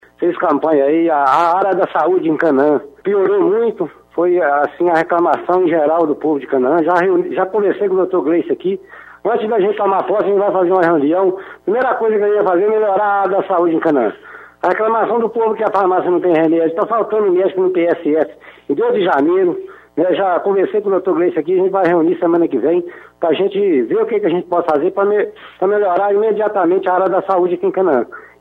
EM ENTREVISTA NOVO PREFEITO DE CANAÃ DESTACA FALTA DE MÉDICOS E MEDICAMENTOS NO POSTO DE SAÚDE E FALA EM MUDANÇAS NO SECRETÁRIADO
Em entrevista a Rádio Montanhesa Viçosa na manhã desta segunda-feira (03), Tião afirmou, que um de seus maiores desafios neste novo mandato é em relação ao sistema de saúde municipal. Segundo ele a população reclama da falta de médicos no unidade de saúde e no Programa Saúde da Família e também da falta de medicamentos na farmácia municipal.
ENTREVISTA-PREFEITO-DE-CANAÃ-SAÚDE.mp3